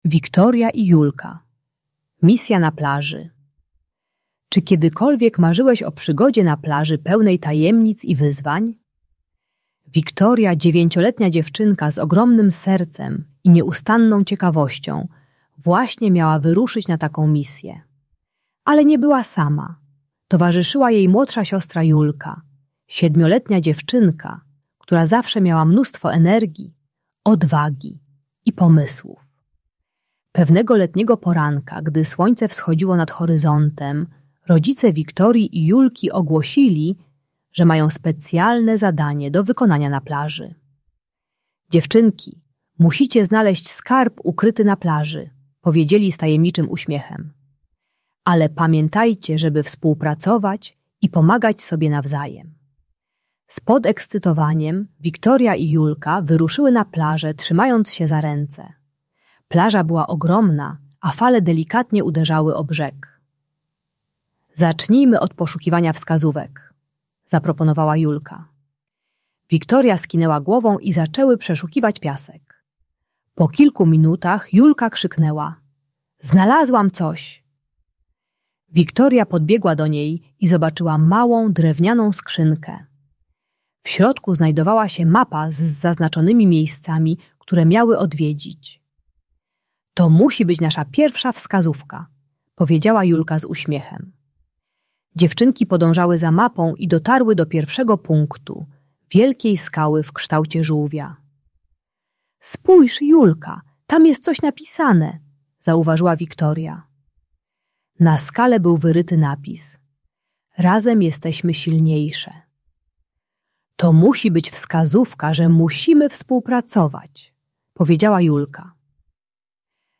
Wiktoria i Julka: Misja na Plaży - Rodzeństwo | Audiobajka